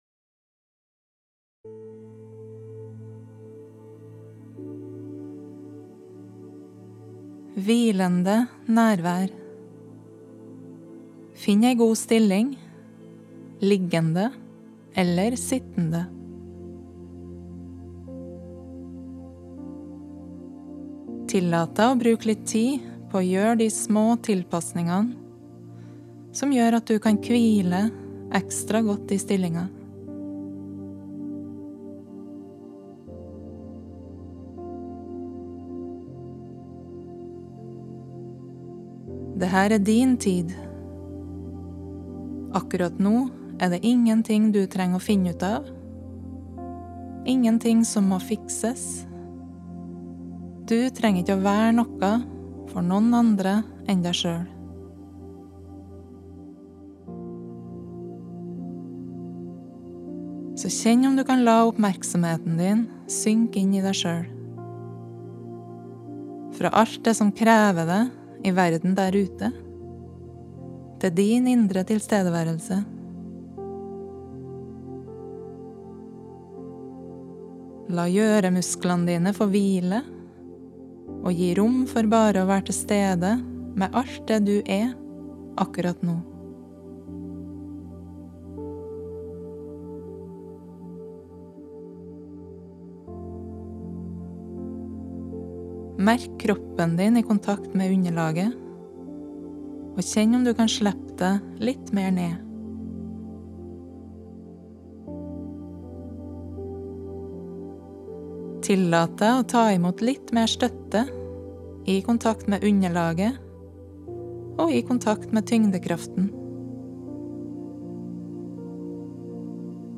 Her finner du Hvilende nærvær, en gratis lydfil for avspenning og meditasjon.
Meditasjonene er lest inn i studio, og har samme lydkvalitet som Hvilende nærvær.